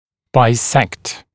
[baɪ’sekt][бай’сэкт]делить пополам, рессекать пополам (о линии)